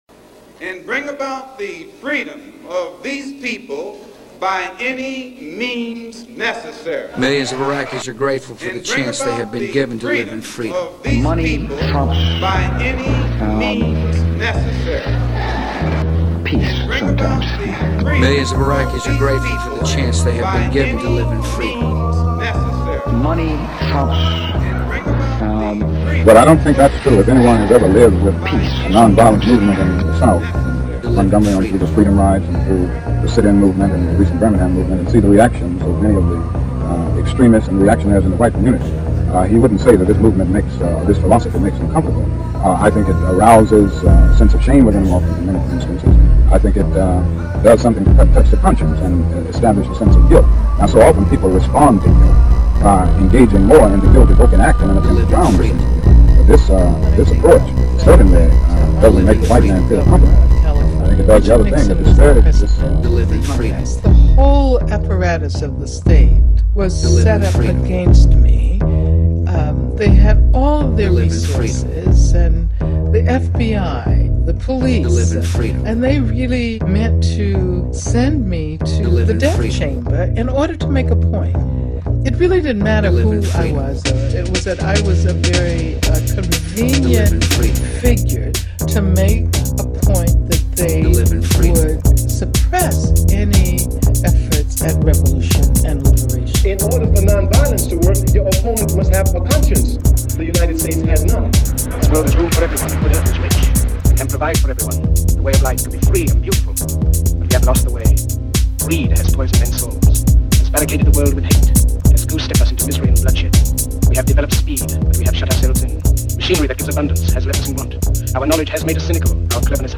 Here is my latest audio art piece “Acoustic Discourse On Violence.” The piece explores a working logic of non-violent action that is complicated by visceral reactions to fear of the unknown, shame at our moral complicity and responses to aggression.